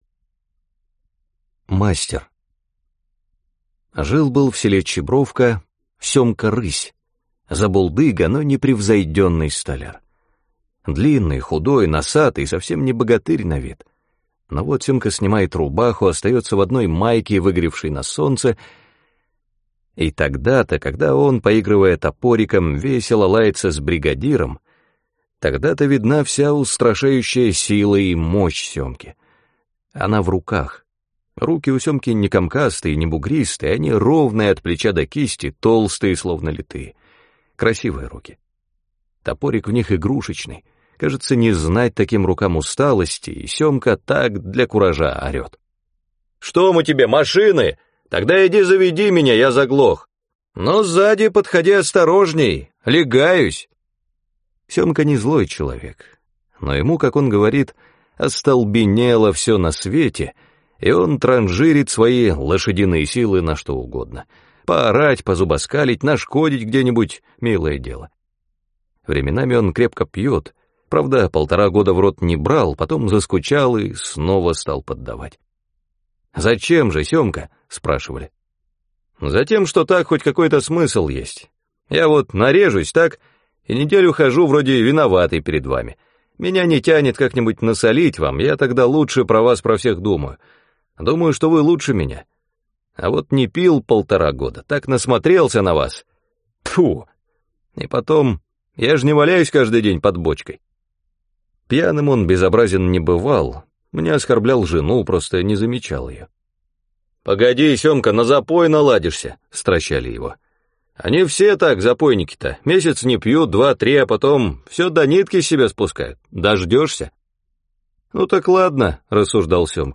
Мастер — слушать аудиосказку Василий Шукшин бесплатно онлайн